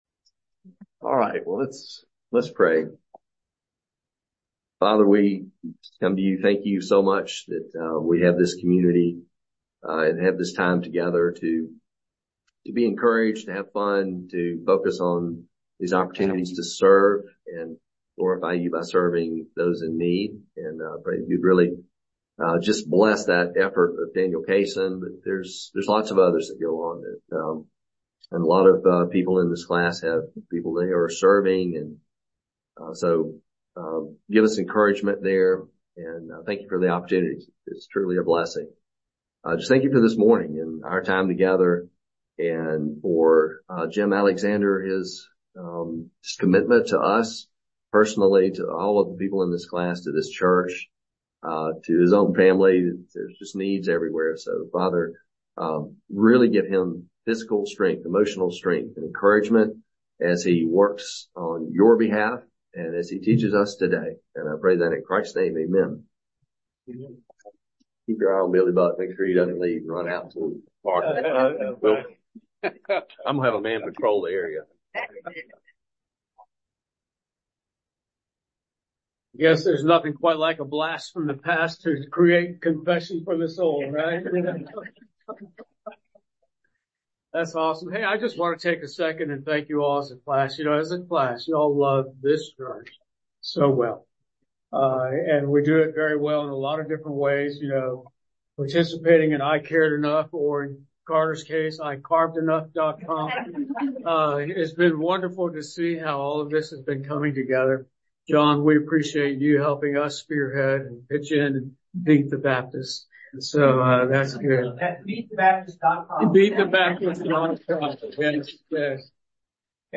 teaching on the Ten Commandments